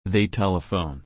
/ˈtɛlɪfəʊn/